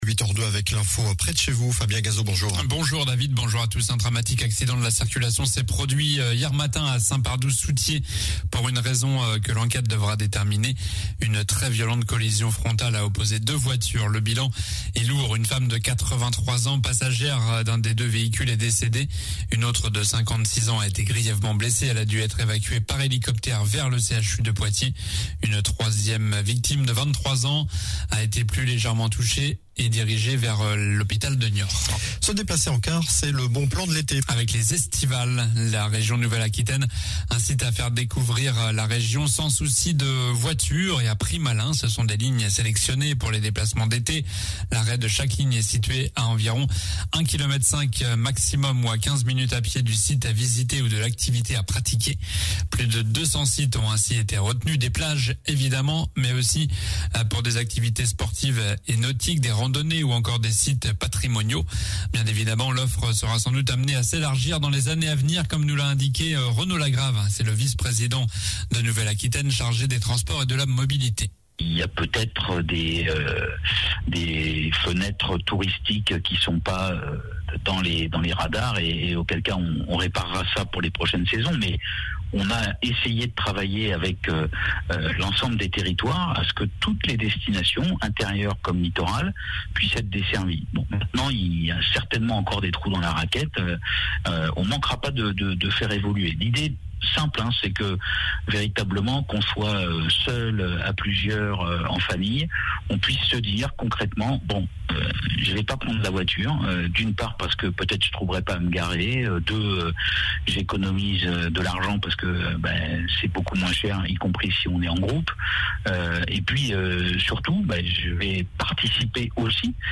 Journal du jeudi 08 juillet (matin)